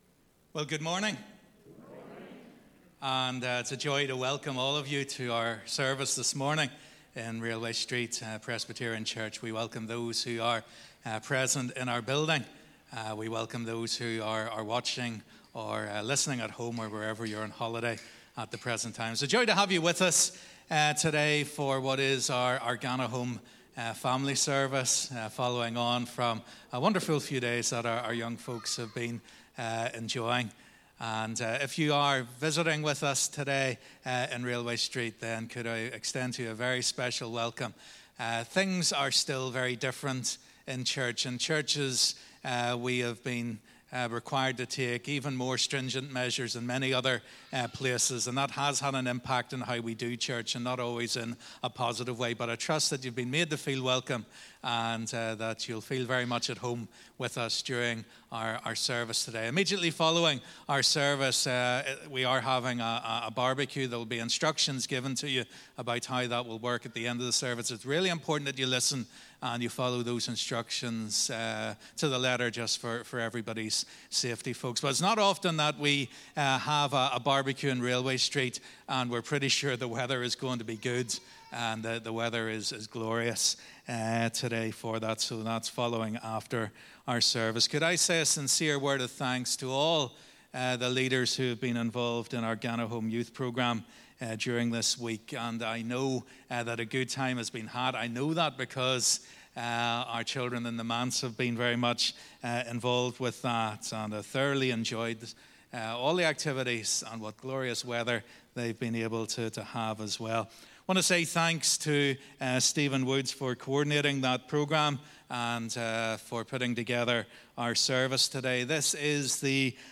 GanaHOME Family Service